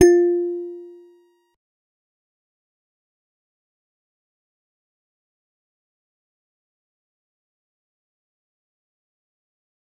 G_Musicbox-F4-mf.wav